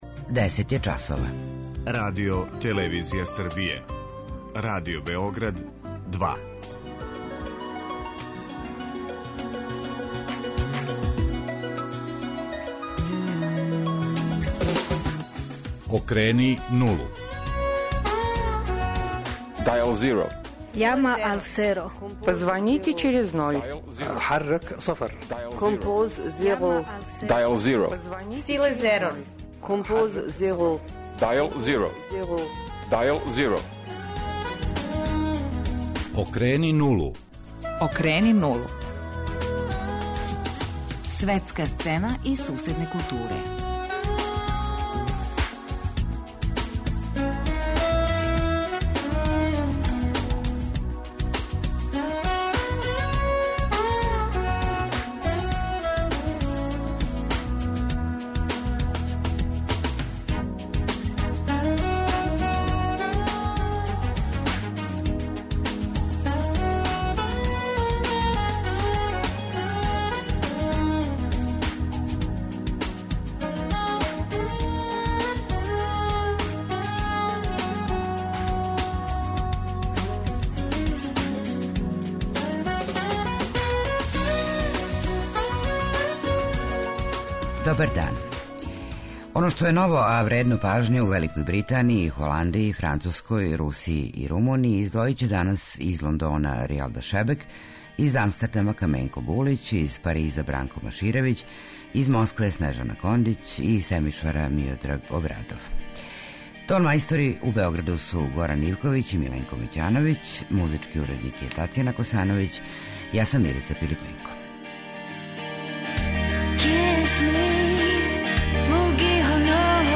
У студију у Београду